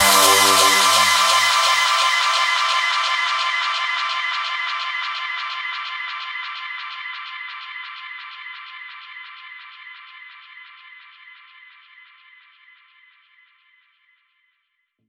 VEC3 FX Athmosphere 17.wav